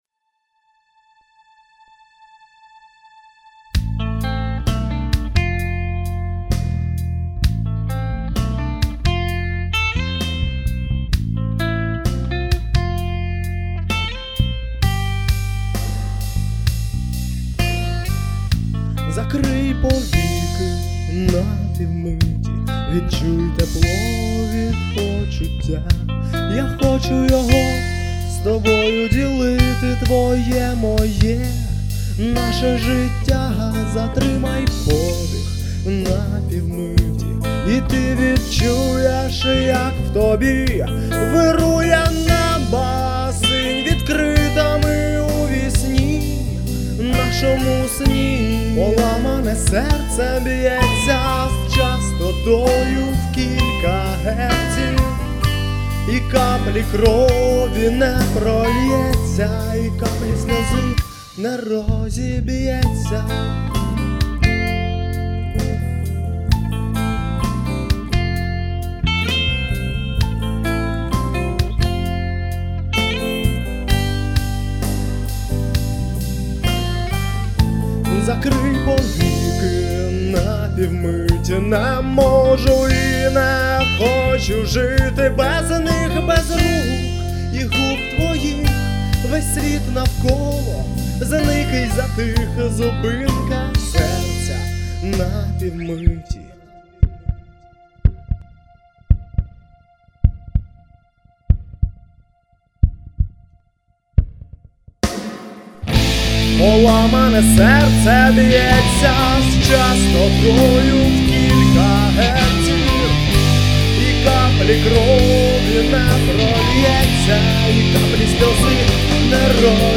гитара, вокал
бек вокал
басс-гитара
ударник
Стиль исполнения – поп-рок.